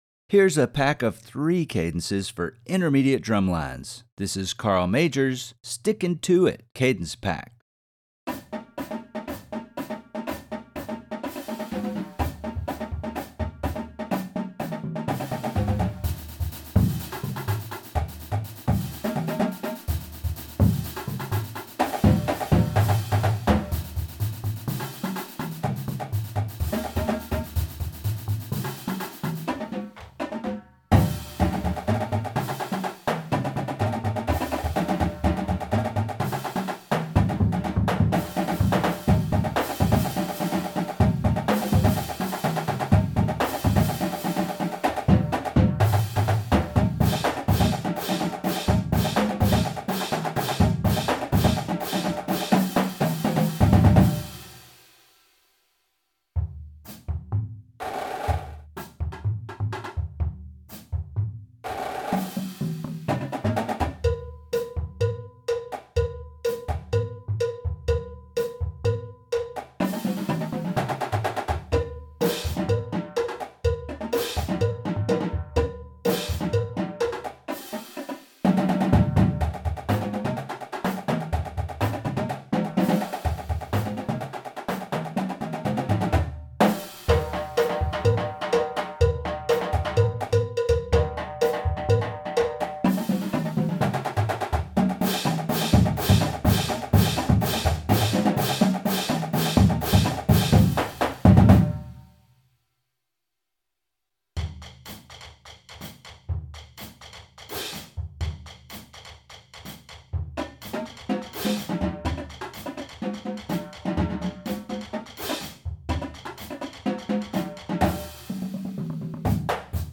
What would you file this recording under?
Voicing: Percussion Feature